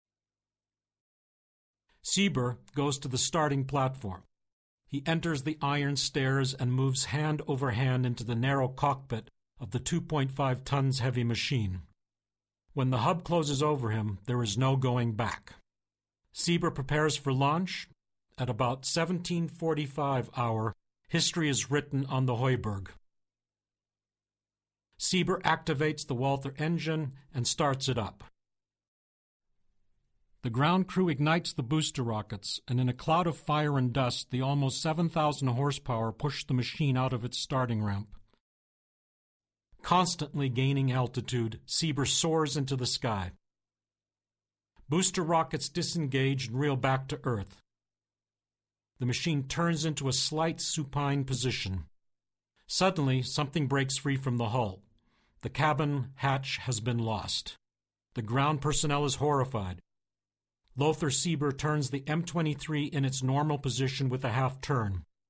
Sprechprobe: Sonstiges (Muttersprache):
Excellent on educational, industrial, promotional voice-over (English-USA). Voice range - 35-60 years